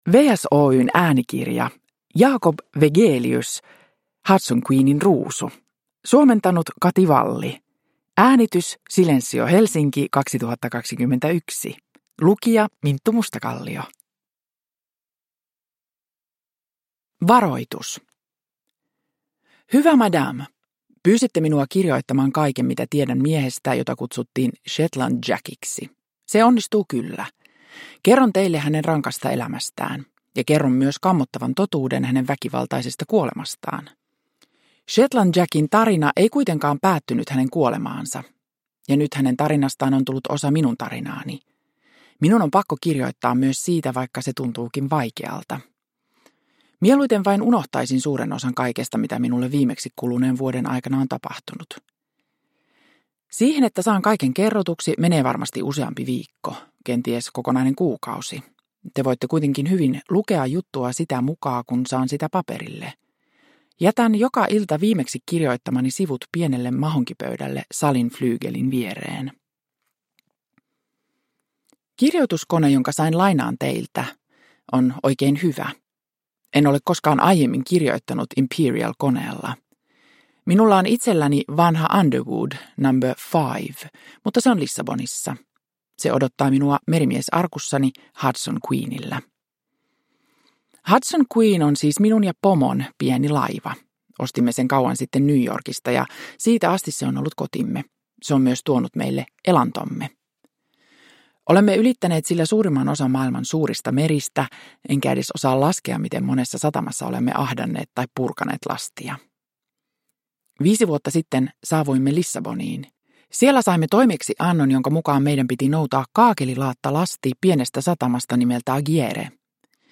Hudson Queenin ruusu – Ljudbok – Laddas ner